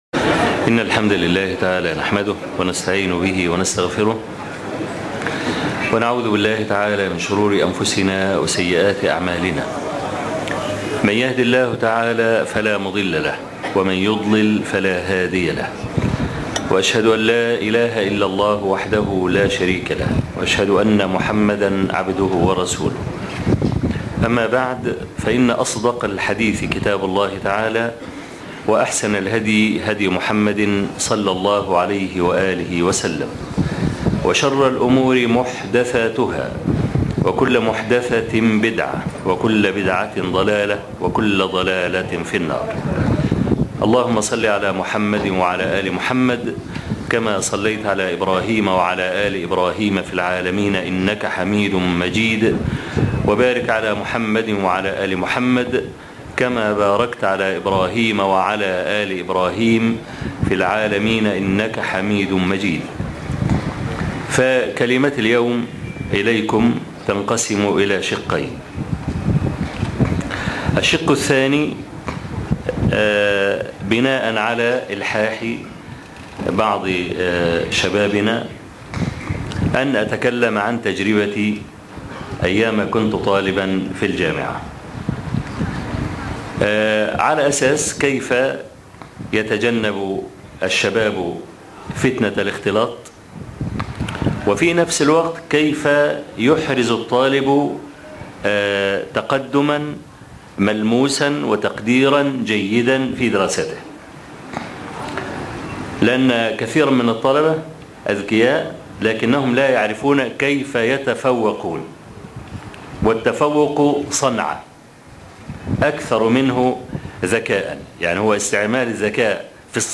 لقاء الشيخ أبو إسحاق بشباب كلية التجارة -كفر الشيخ - الشيخ أبو إسحاق الحويني